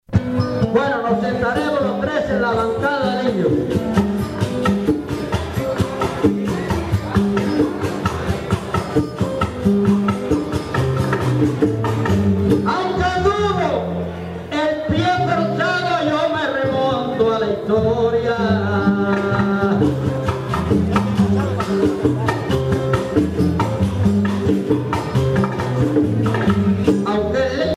Pièces musicales
Sancti Spiritus, Cuba
Pièce musicale inédite